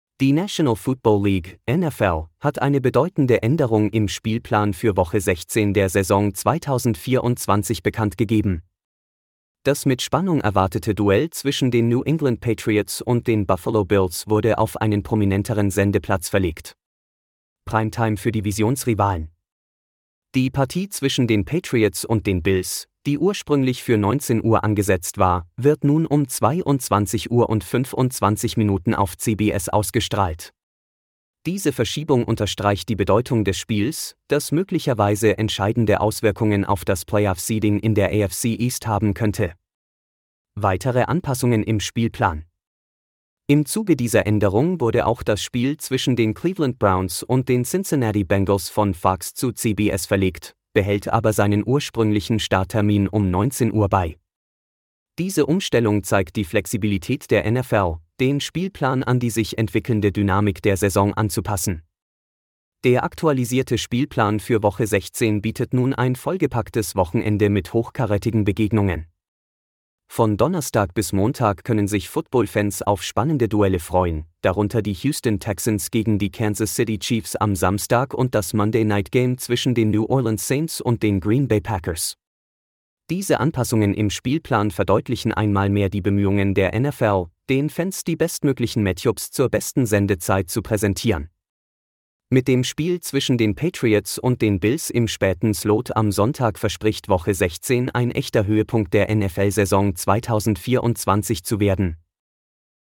Diese Audioversion des Artikels wurde künstlich erzeugt und wird stetig weiterentwickelt.